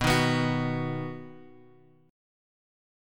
B chord {x 2 1 x 0 2} chord
B-Major-B-x,2,1,x,0,2.m4a